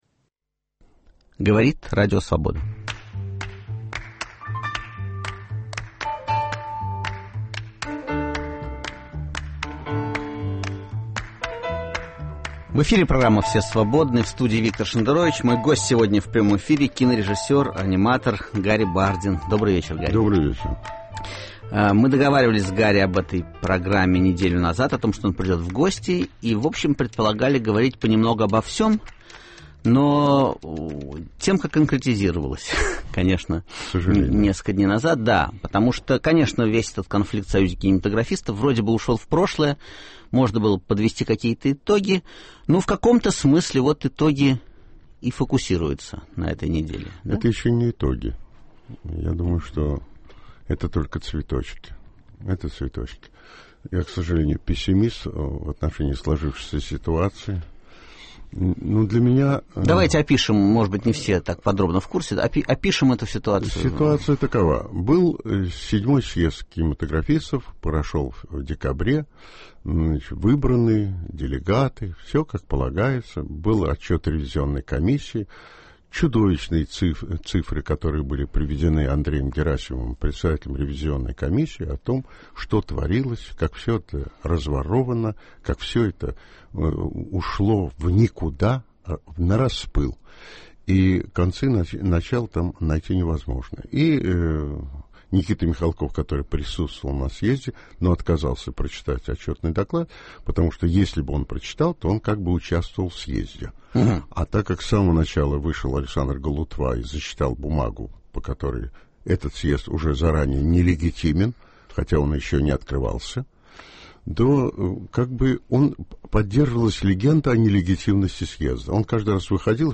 В гостях у Виктора Шендеровича – аниматор, режиссёр Гарри Бардин.